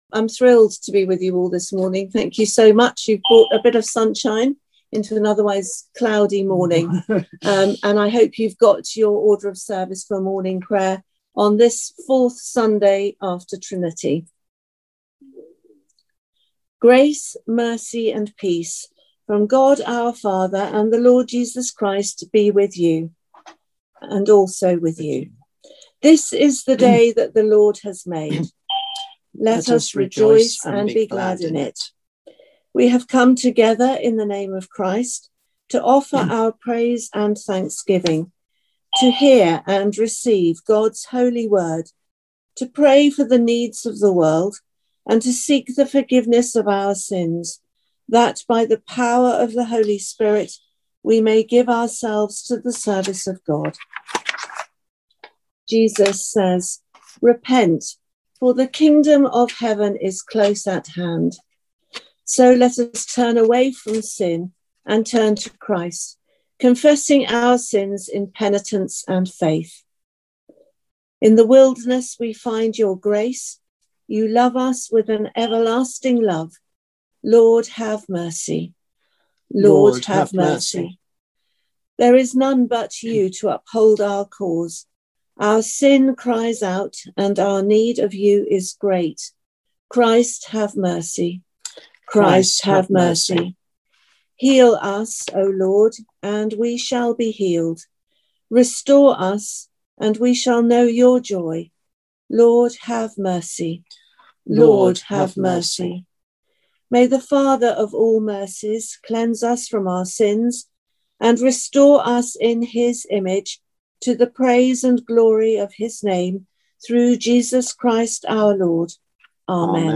Morning Prayer - Sun, 27 Jun 2021 (27:03 / 16.86MB)